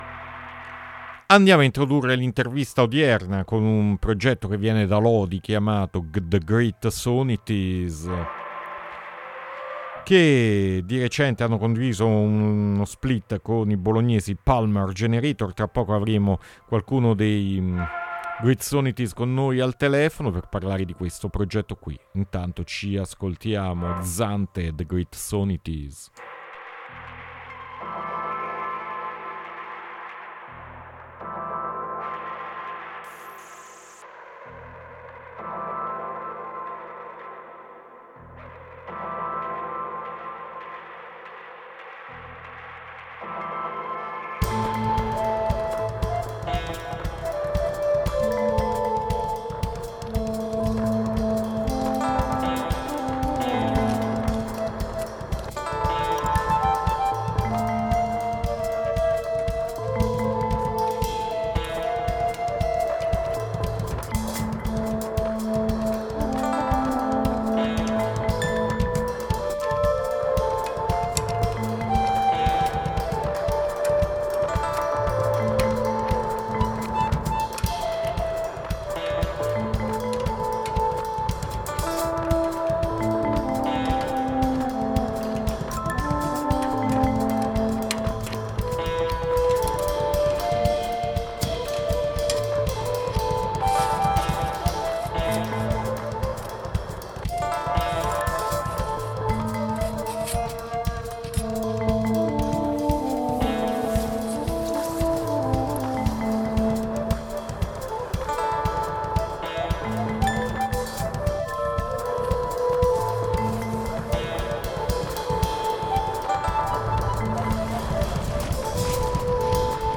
Le atmosfere ci si muove tra abrasivo noise, sperimentazioni e psichedelia occulta